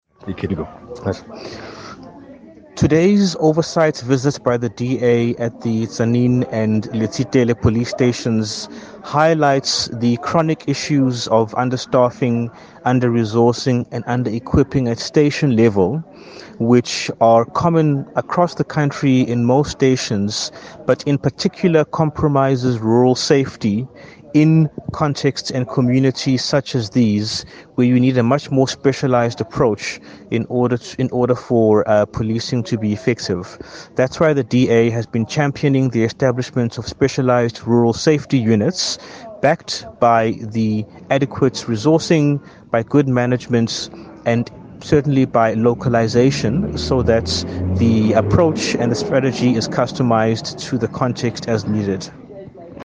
English by Mr Mbhele and an Afrikaans